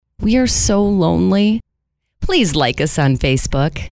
Category: Radio   Right: Both Personal and Commercial
Tags: VO Voiceover Drops Radio Radio Drops Facebook Facebook Drops FB Like Like Us Like Facebook Liking Facebook Female Voice Female Woman Woman Voice Woman Talent Professional Woman Professional Female Voiceover Female Professional Female Drops Radio Drops. Female Drops